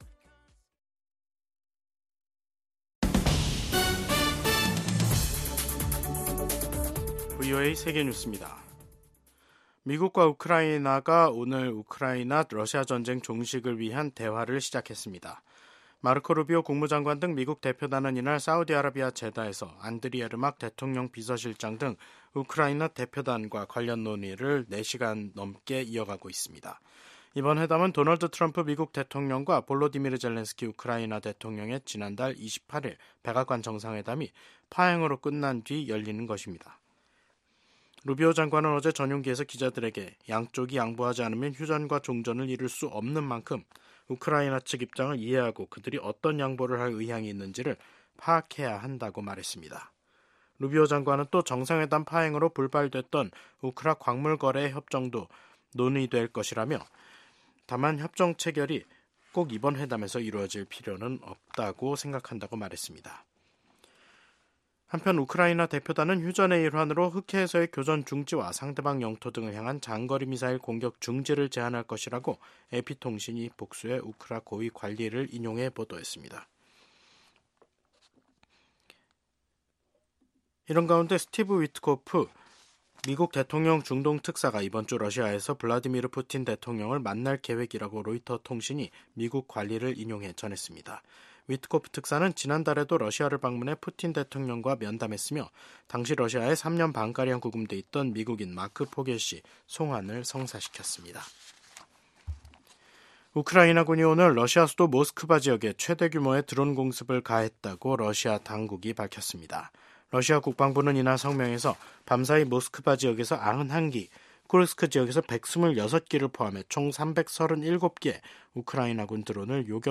VOA 한국어 간판 뉴스 프로그램 '뉴스 투데이', 2025년 3월 11일 3부 방송입니다. 미국 정부가 도널드 트럼프 대통령 취임 후 이뤄진 북한의 첫 탄도미사일 발사와 관련해 북한의 완전한 비핵화 원칙을 강조했습니다. 미북 간 대화에서 한국이 배제되는 일은 절대 없을 것이라고 주한 미국 대사대리가 밝혔습니다.